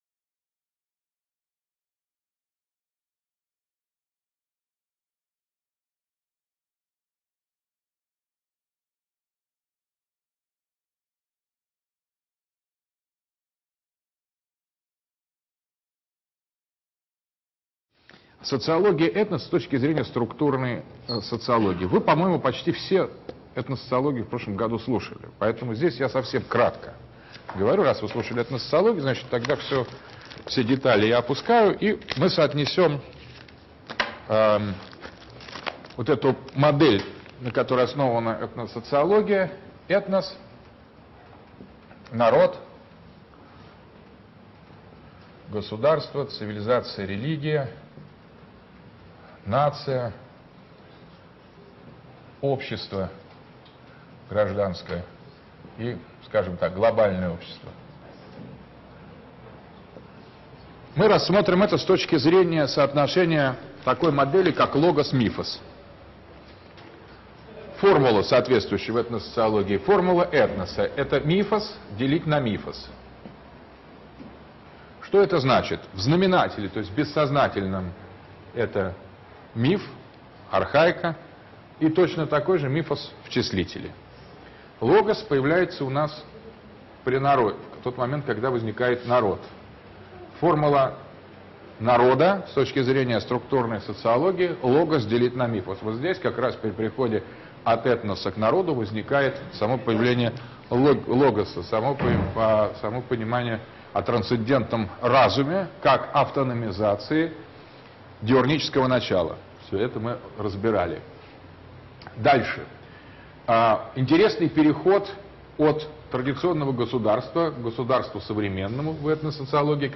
Читает А.Г. Дугин. Москва, МГУ, 2010.